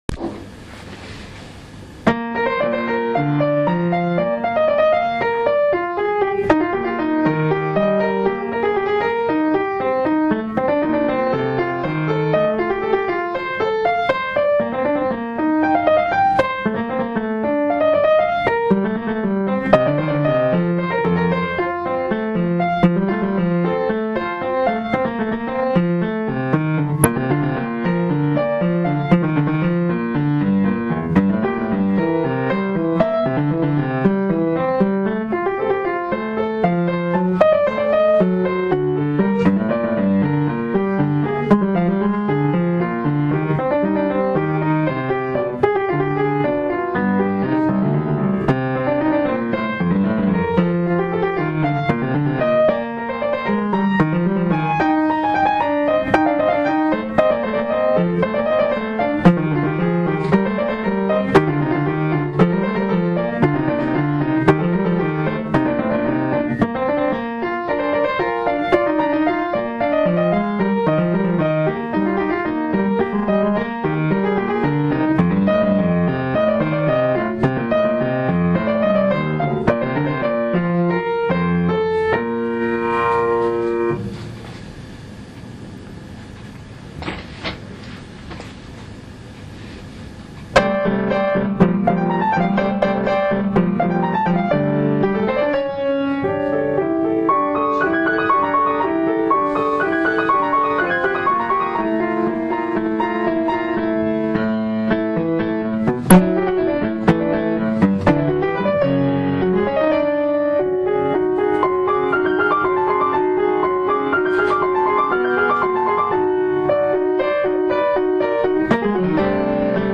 一部ですがメンバーの演奏をご紹介します。